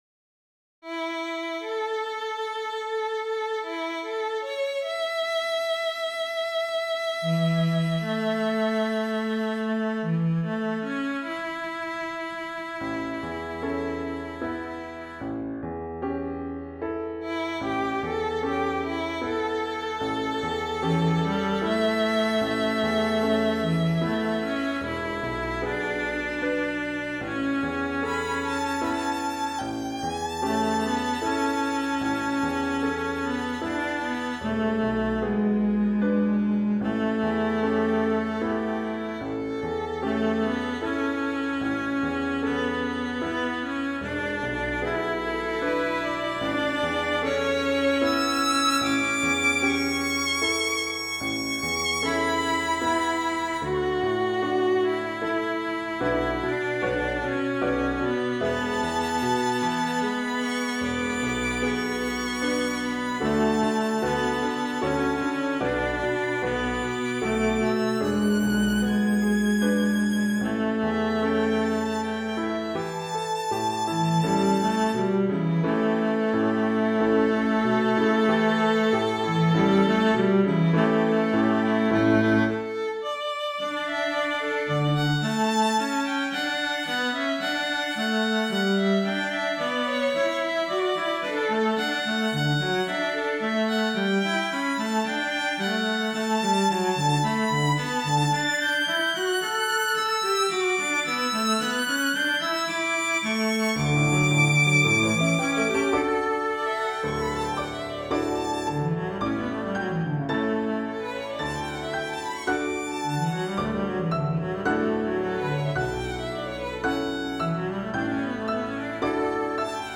Piano, Violin, Cello
Christian, Gospel, Sacred, Praise & Worship.